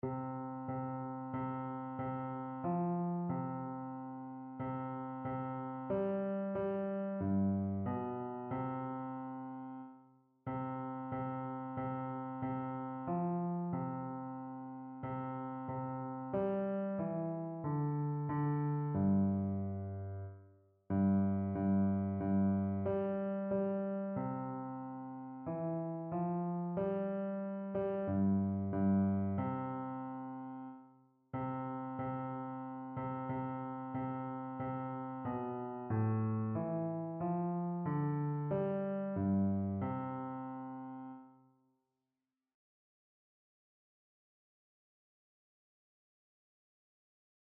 Einzelstimmen (Unisono)
• Bass [MP3] 741 KB